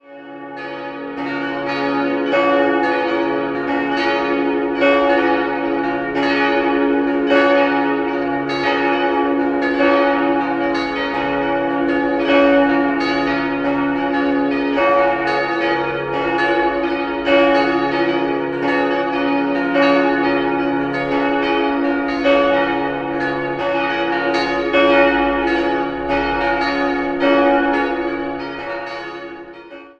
Jahrhundert stammt die Kanzel, der Taufstein bereits von 1582. 5-stimmiges Geläute: des'-f'-as'-b'-des'' Die Glocken wurden im Jahr 1954 von Friedrich Wilhelm Schilling in leichter Rippe gegossen.